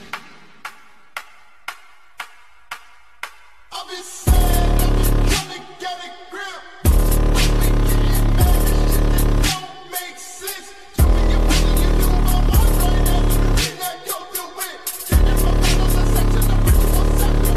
Tap sound Pt ✌🏼 ( sound effects free download